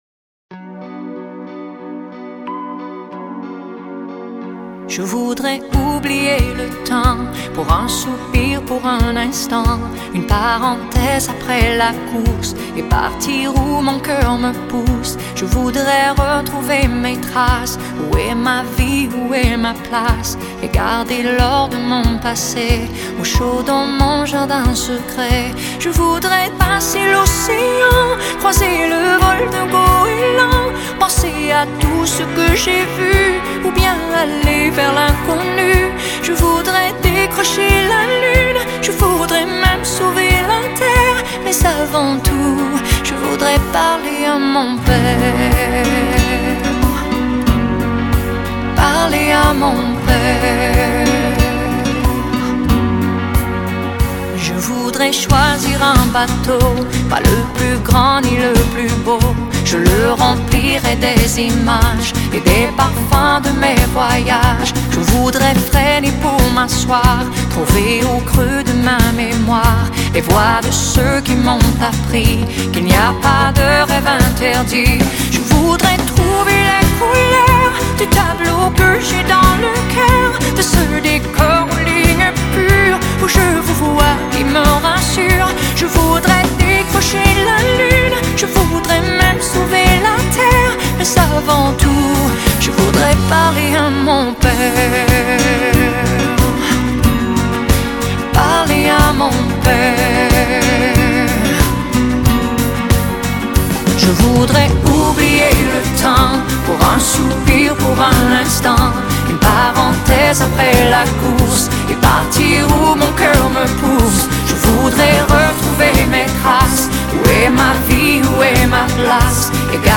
以亲情之爱为根基 用最动容的嗓音细腻刻画无悔的爱
谱以中版的抒情摇滚节拍